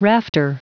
Prononciation du mot rafter en anglais (fichier audio)
Prononciation du mot : rafter